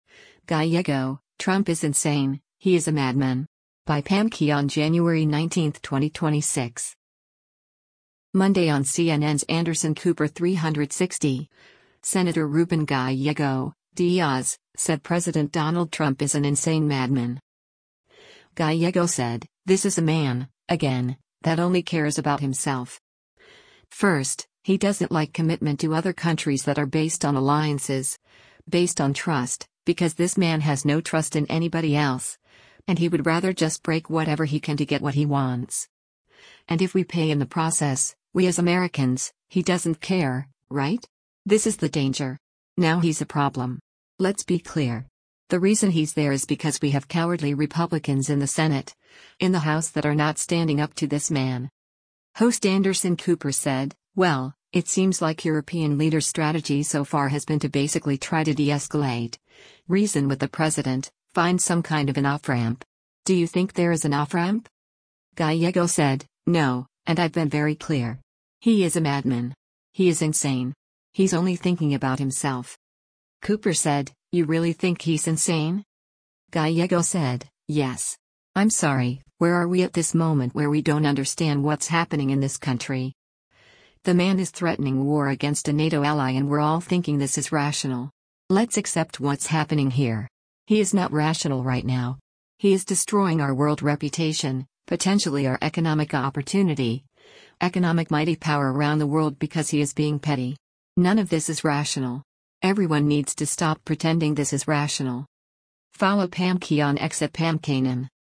Monday on CNN’s “Anderson Cooper 360,” Sen. Ruben Gallego (D-AZ) said President Donald Trump is an “insane” madman.